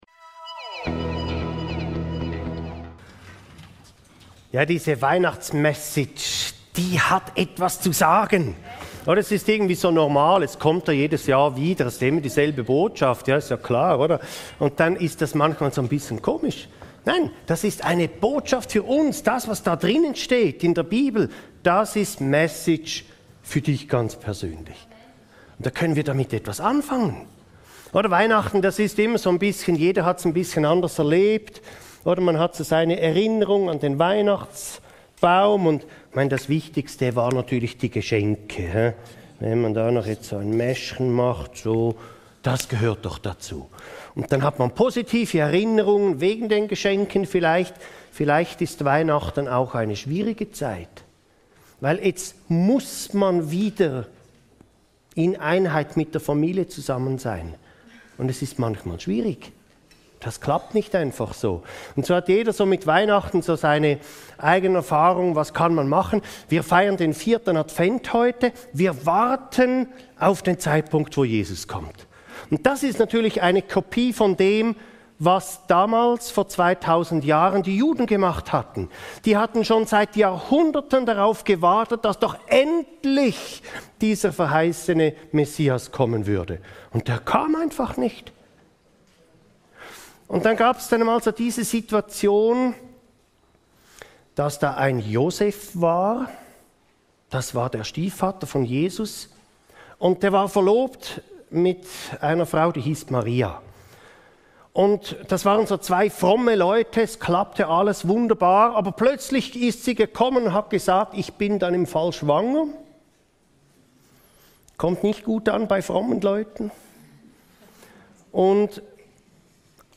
Jesus rettet – wovor denn? (Mt 1,21) ~ Your Weekly Bible Study (Predigten) Podcast